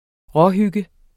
Udtale [ ˈʁʌˌ- ]